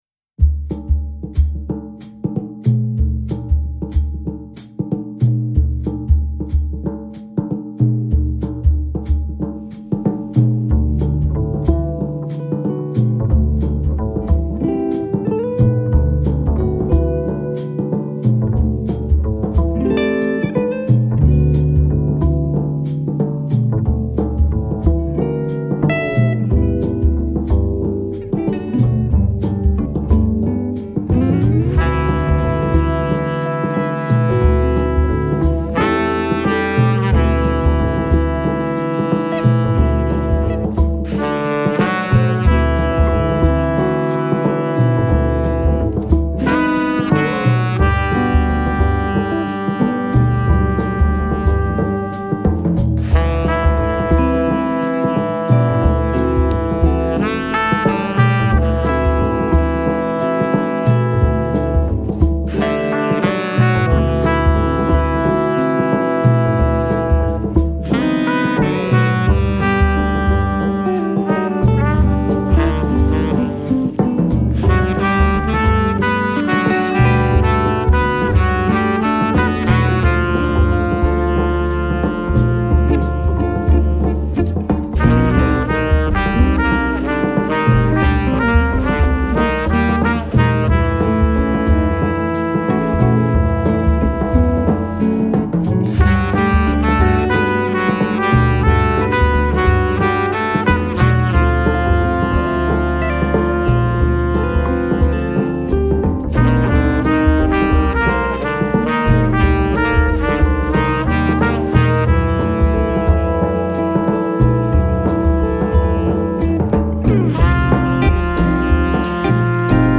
Trumpet
Tenor Saxophone
Upright Bass
Guitar
Drums